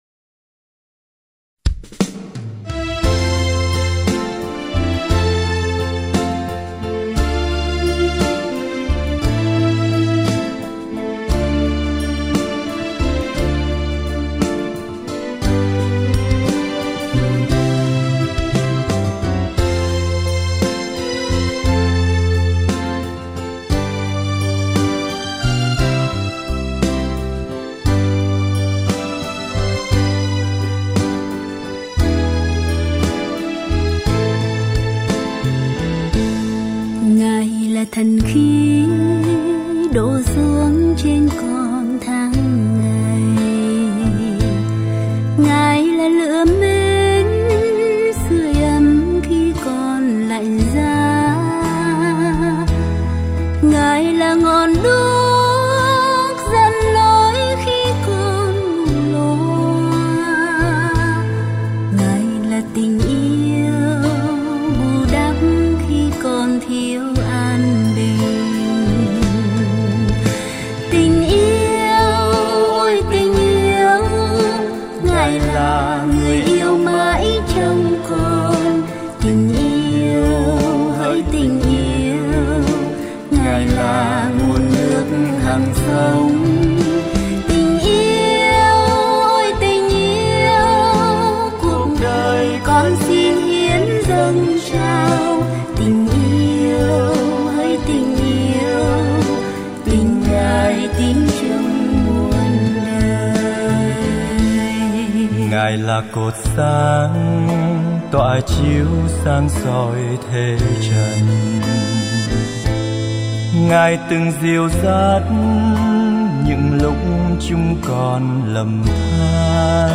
Thánh Ca: